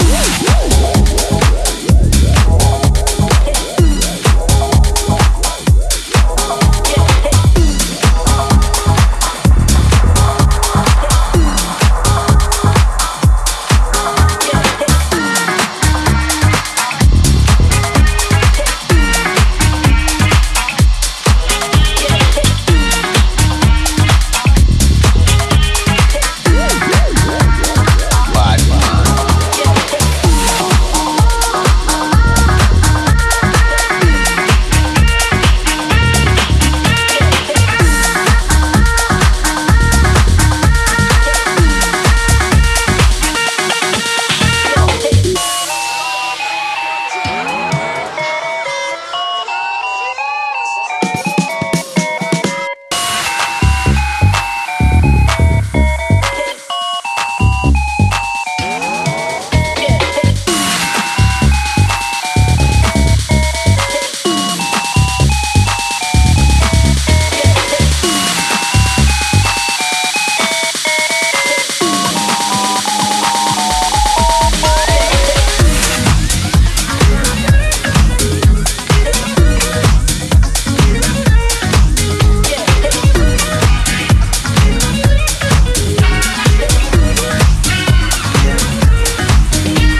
際立ってパワフルな内容に仕上がっています！